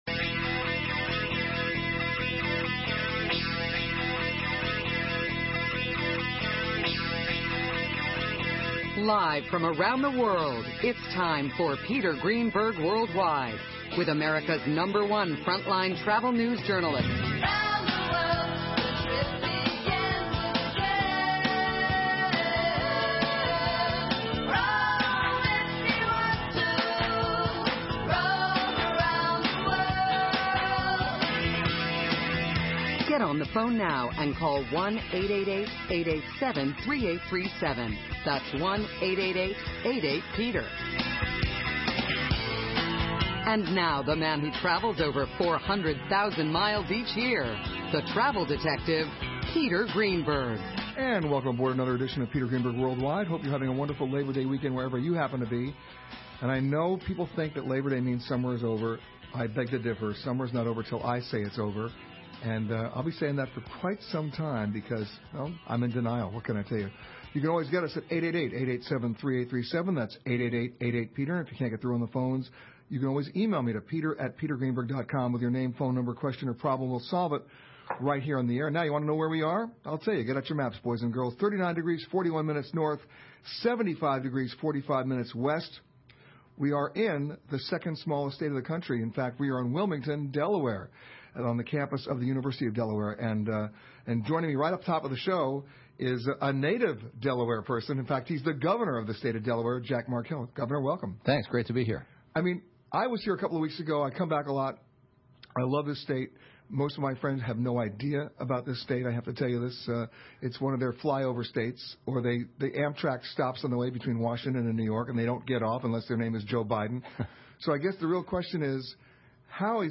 – Jack Markell, governor of Delaware, talking about surprising facts about this tiny state, including a plan to reduce energy consumption 15 percent by 2015,
Standard Podcast Play Now | Play in Popup | Download Radio Guests – Delaware – Sept. 4, 2010 On Saturday, September 4, Peter will broadcast his radio show from The University of Delaware in Newark, Delaware.
Plus he’ll be taking your calls and answering your travel problems on the air!